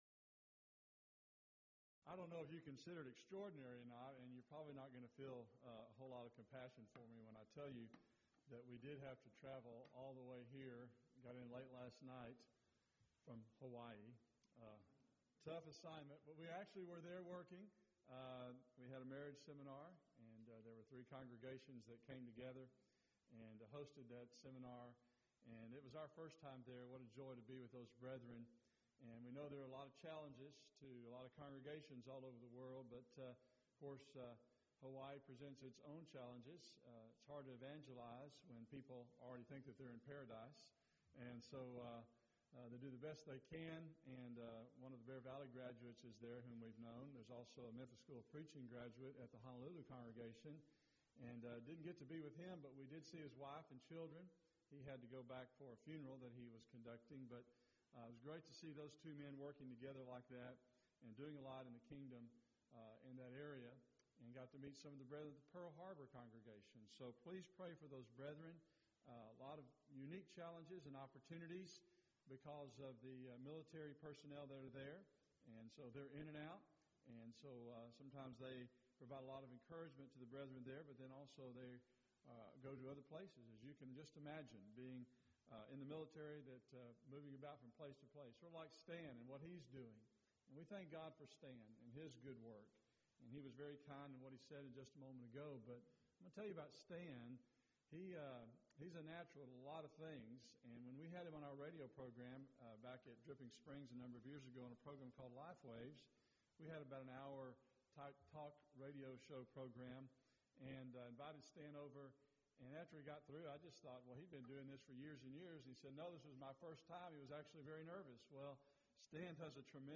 Event: 16th Annual Schertz Lectures Theme/Title: Studies in Genesis
lecture